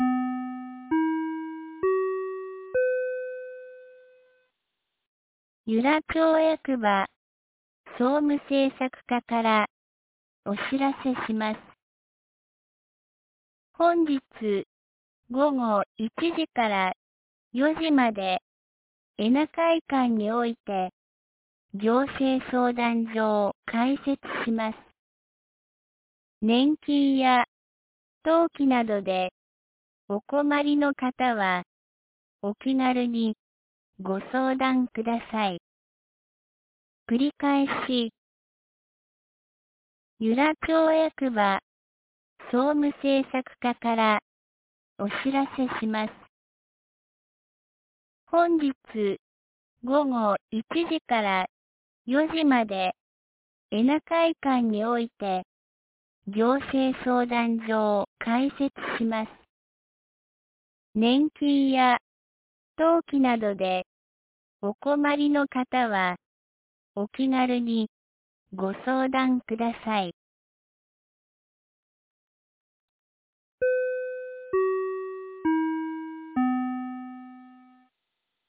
2020年10月15日 12時21分に、由良町から全地区へ放送がありました。